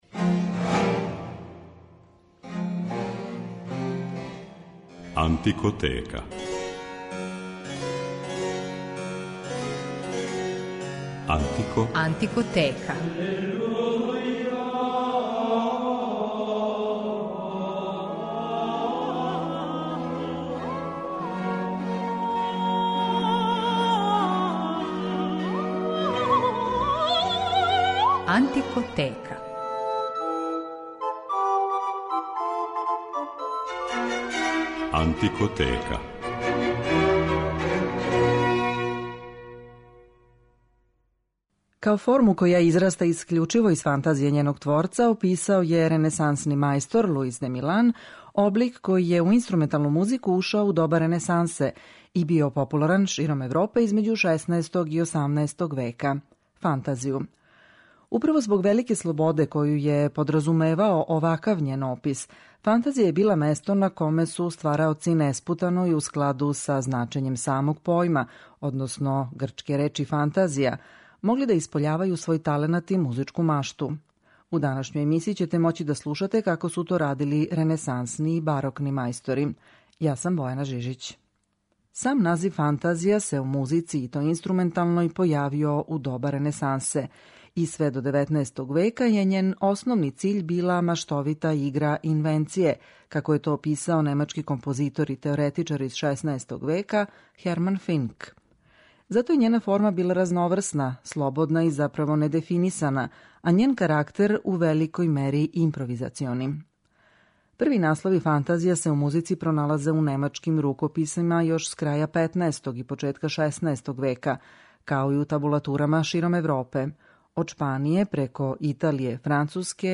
У данашњој Антикотеци ћете моћи да слушате како су то радили ренесансни и барокни мајстори .
Моћи ћете да чујете како је, по закључцима једног британског музиколога и у извођењу једног британског хора, заиста звучао чувени "Мизерере " Грегорија Алегрија.